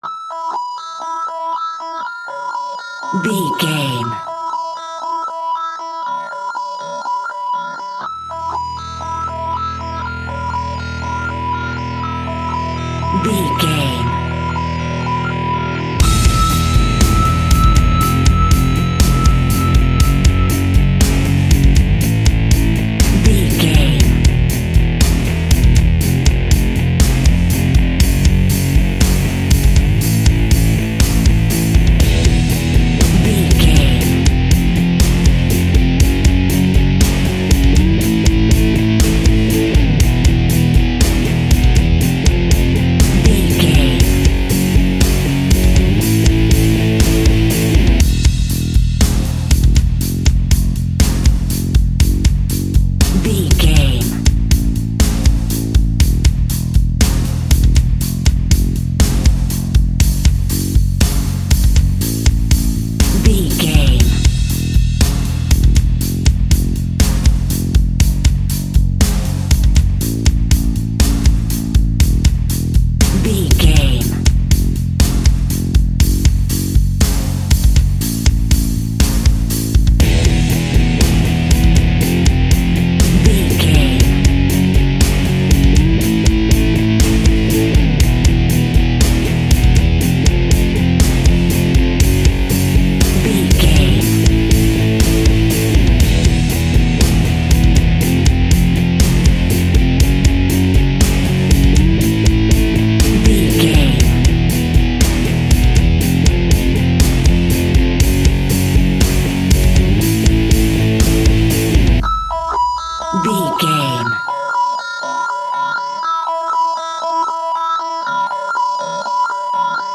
Aggressive Rock Metal Music Full Mix.
Epic / Action
Ionian/Major